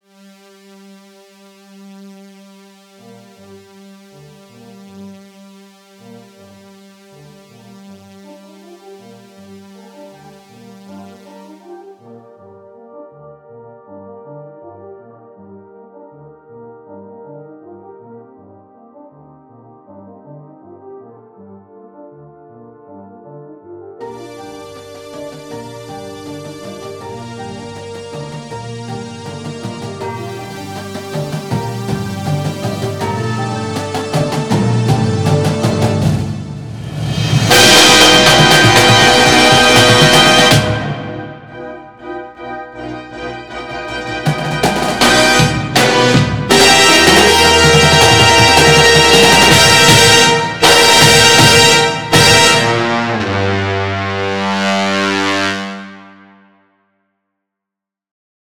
Hello this is my new marching show I'm working on, its meant to be a musical representation of the concept of infinity. The first bits before the hit are muffled because the winds are facing towards side 2 so it should sound more like an echo.